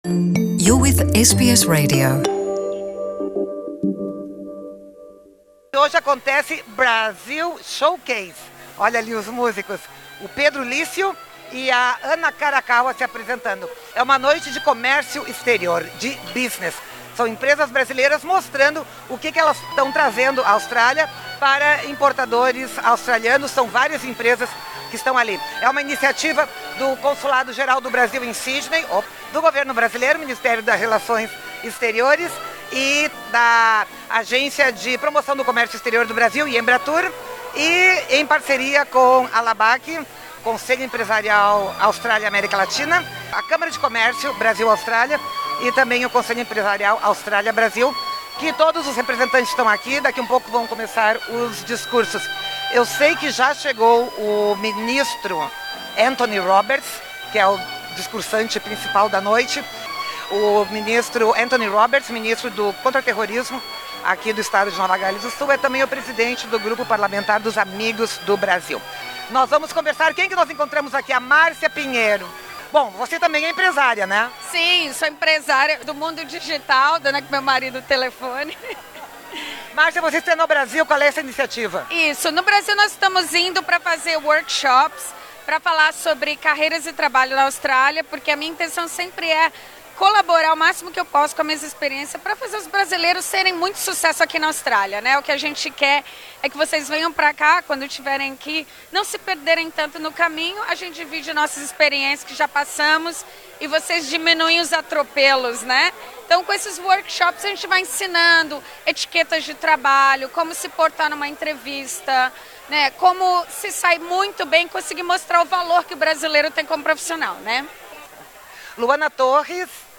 Na quarta-feira, 25 de setembro, aconteceu em Sydney mais uma edição do Brazil Showcase, uma noite empresarial Brasil-Austrália, iniciativa do Consulado Geral do Brasil, no Parlamento de Nova Gales do Sul.